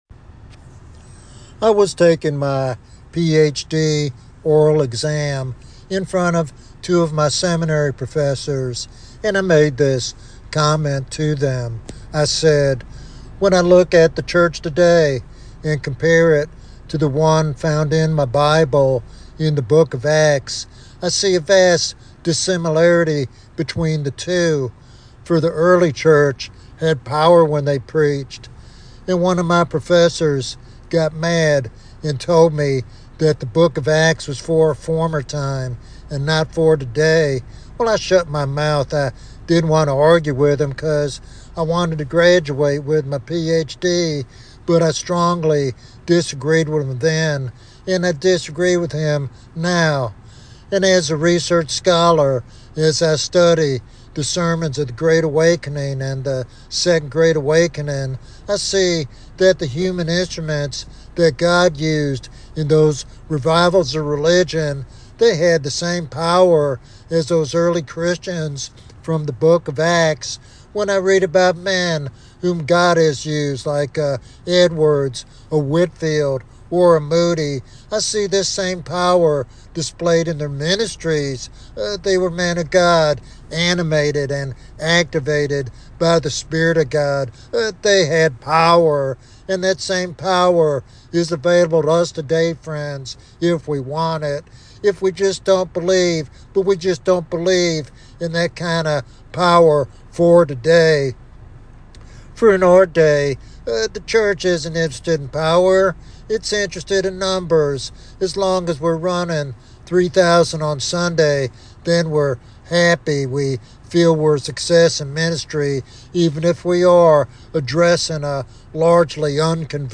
This sermon inspires a hunger for revival and a bold proclamation of the gospel with authority and conviction.